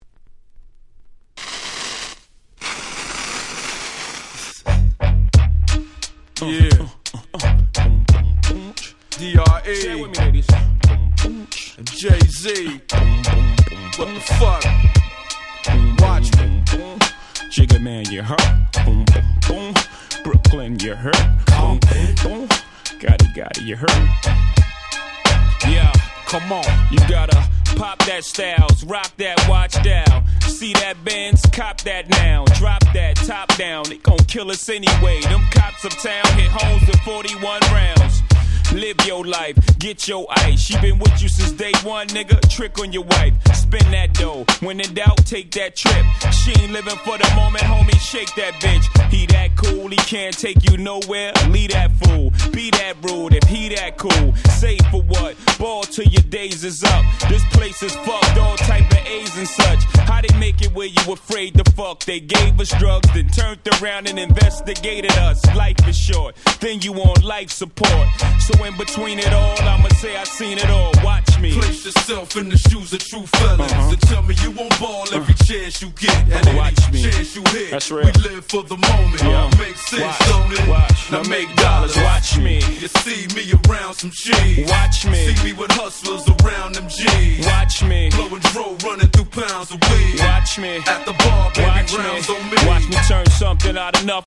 00' Super Hit Hip Hop !!